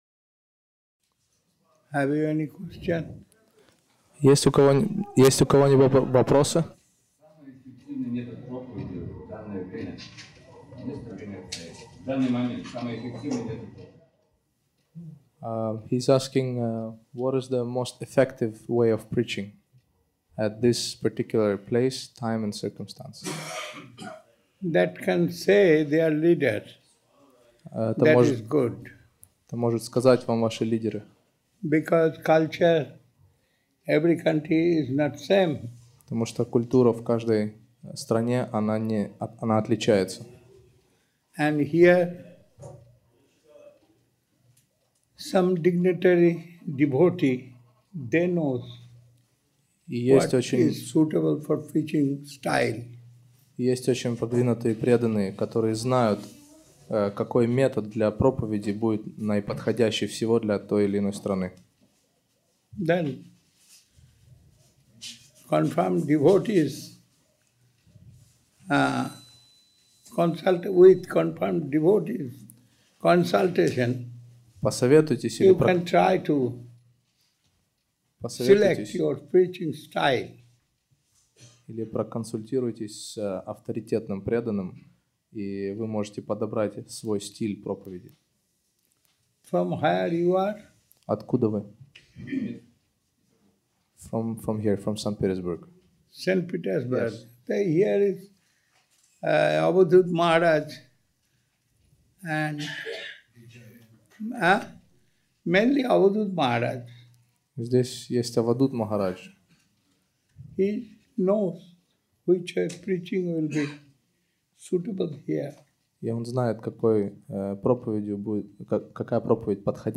Даршаны 2006
Place: Sri Chaitanya Saraswat Math Saint-Petersburg